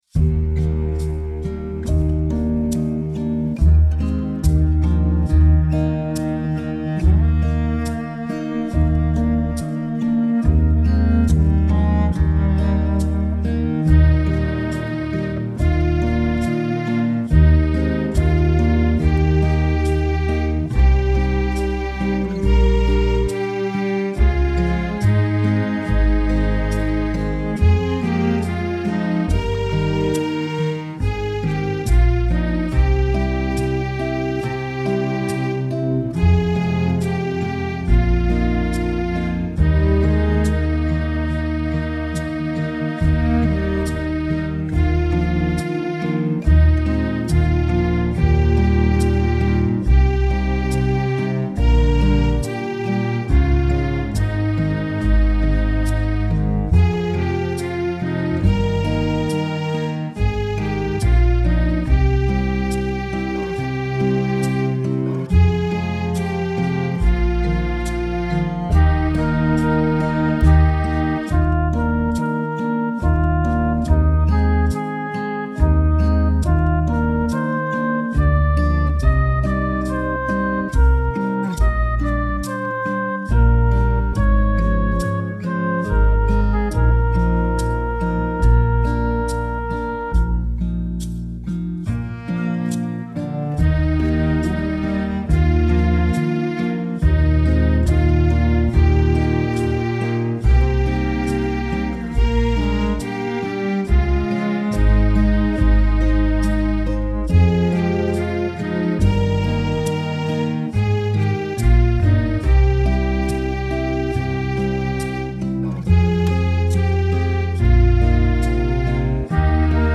You just have to contend with some high notes in the verse.
My backing has the assembly and cantor lines separate.